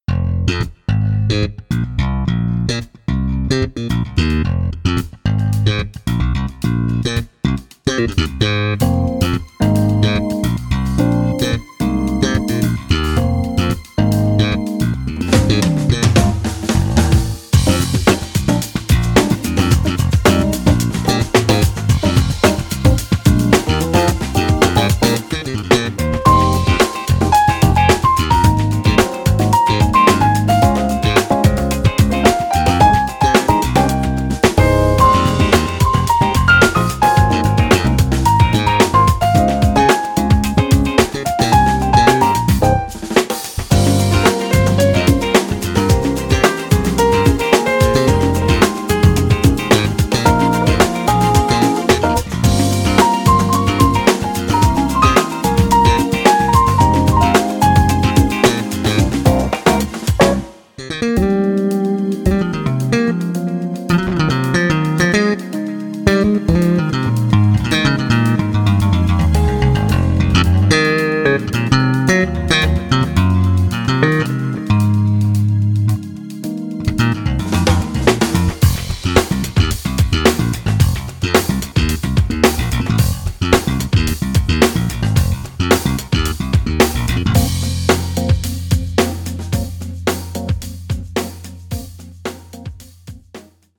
Fat, mix-ready low end